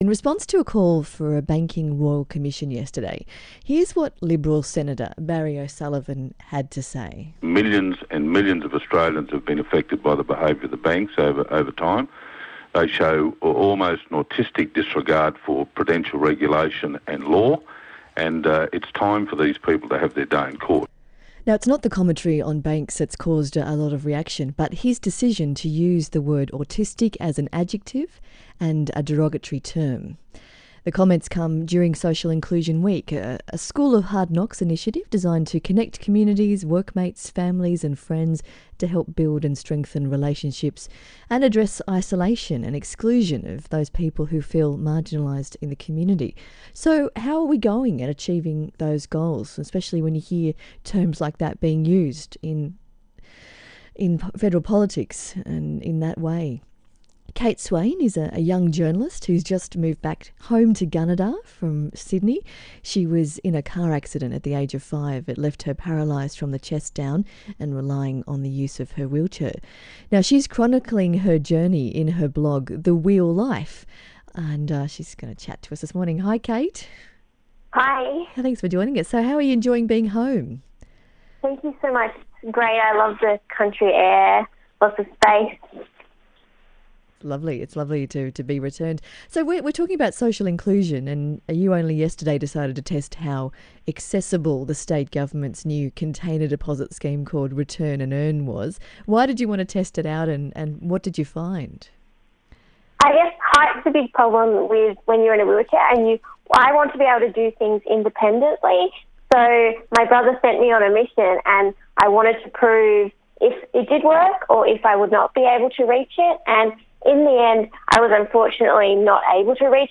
ABC radio interview